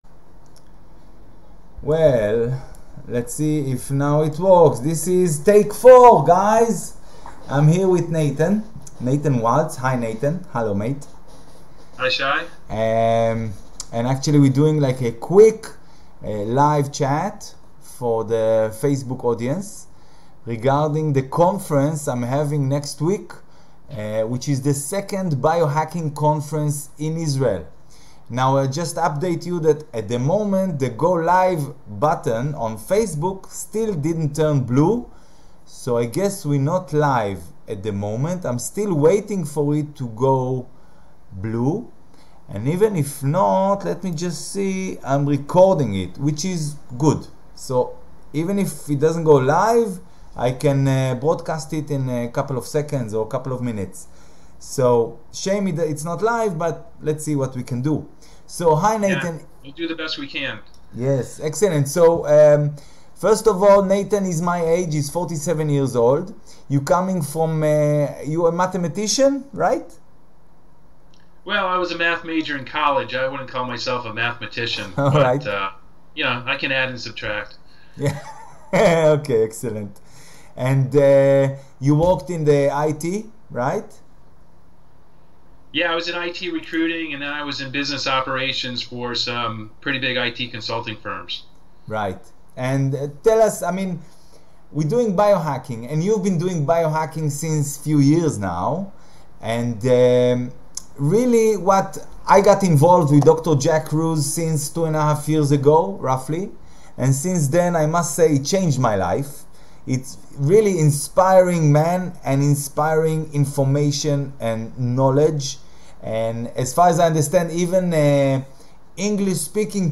ראיון קצר על ביו-האקינג